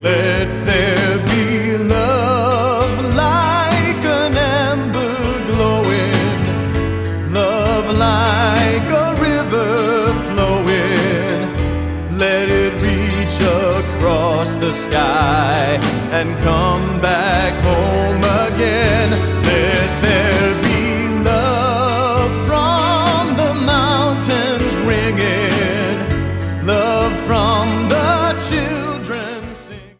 Vocal mp3 Track